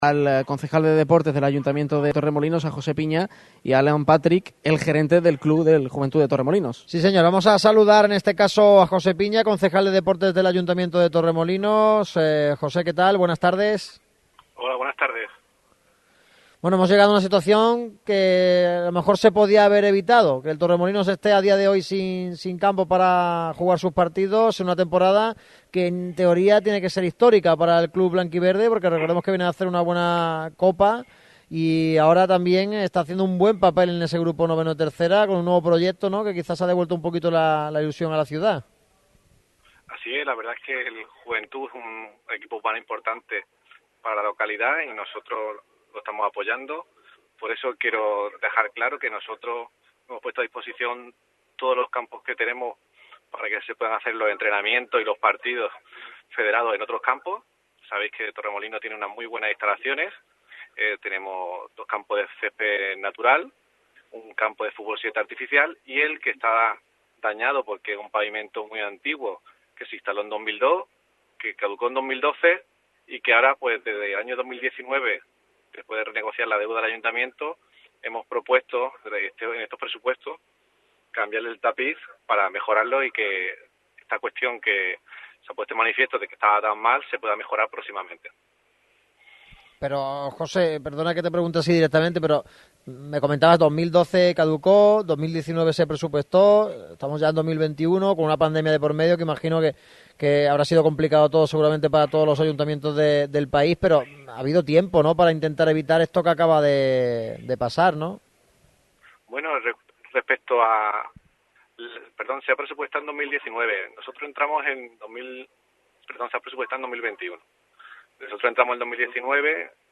ha dado su versión de los hechos en el micrófono rojo.